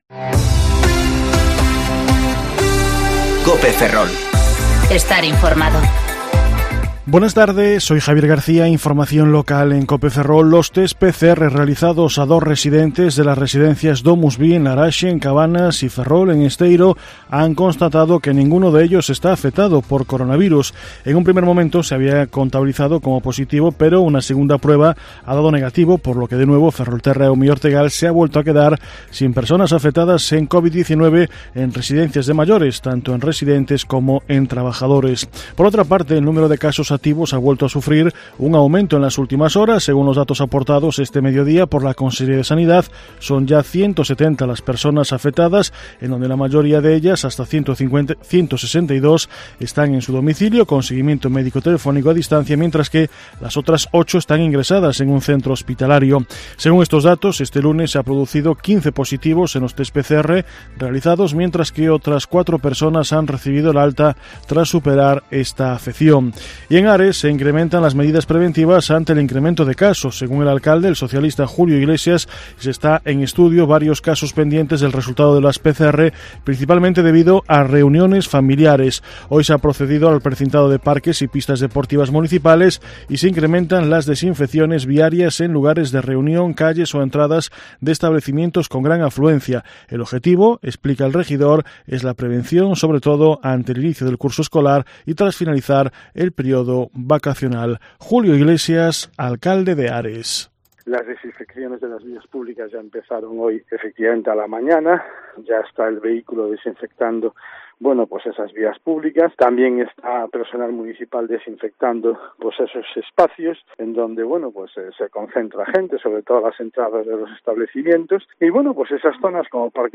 Informativo Mediodía COPE Ferrol 8/9/2020 (De 14,20 a 14,30 horas)